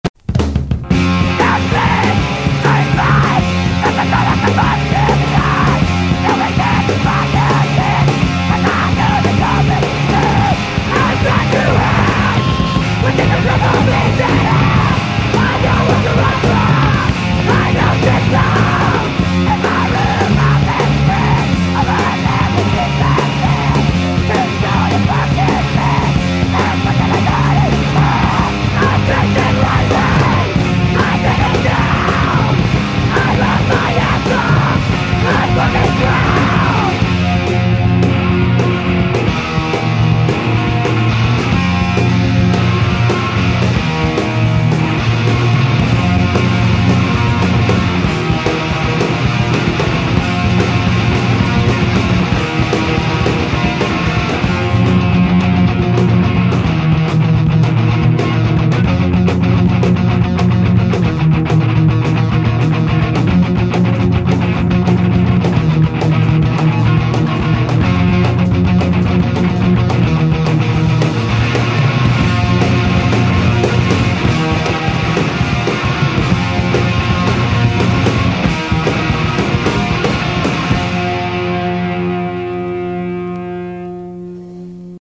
fast and frantic hardcore
catchy songs